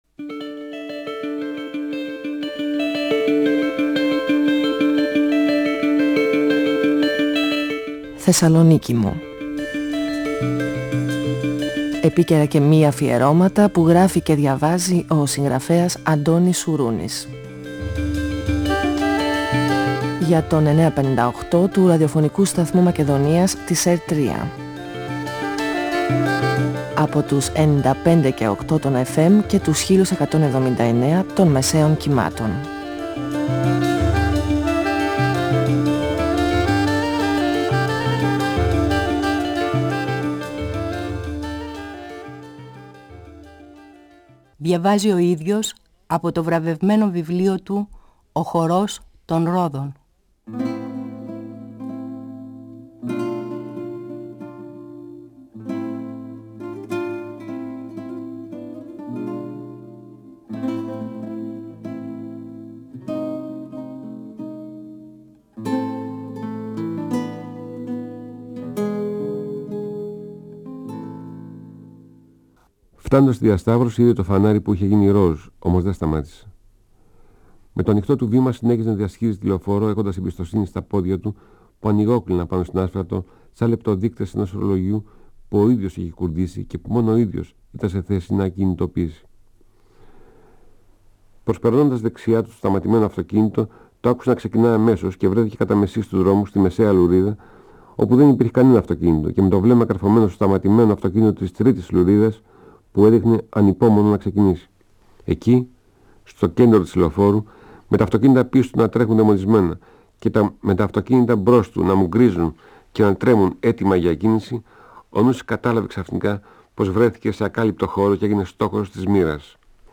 Ο συγγραφέας Αντώνης Σουρούνης (1942-2016) διαβάζει από το βιβλίο του «Ο χορός των ρόδων», εκδ. Καστανιώτη, 1994. Το τροχαίο ατύχημα του Νούση και η δύσκολη καθημερινότητα μετά τον τραυματισμό του. Η αδιαφορία του Άλεξ για τη νέα κατάσταση του Νούση και η αδικία του Καθηγητή στη μοιρασιά των κερδών από το παιγνίδι στο καζίνο.